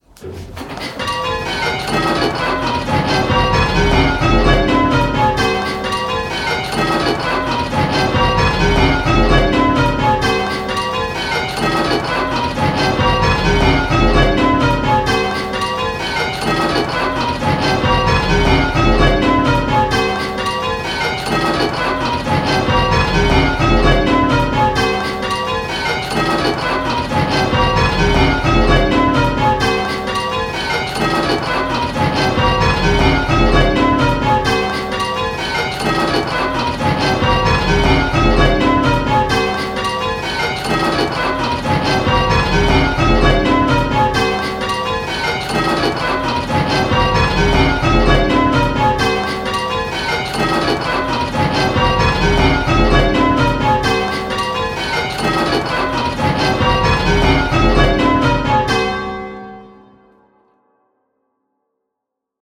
Listen to each clip and choose which bell is ringing early… the faults have been randomly placed, so there is no pattern and there might be consecutive clips that have the same fault!
Click the play button and listen to the rhythm of the 10 bells…. the rhythm will sound a bit lumpy!
Rounds-10-4E.m4a